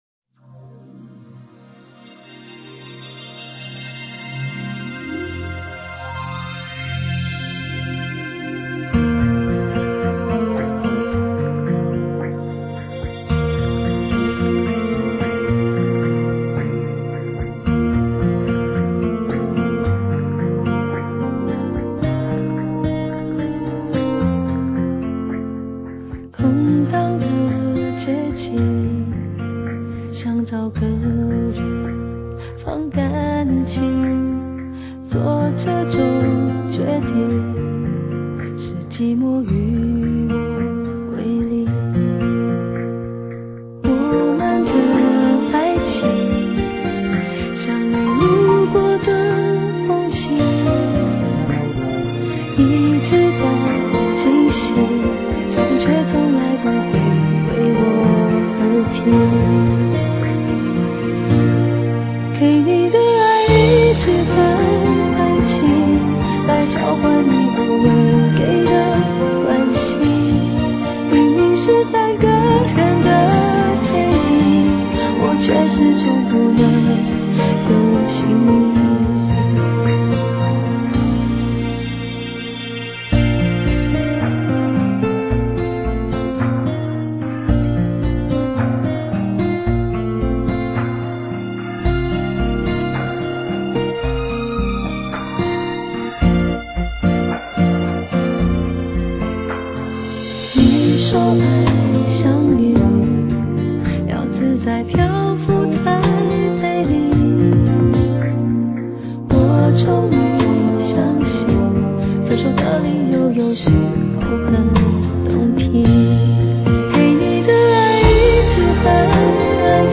是一首淡淡感伤的歌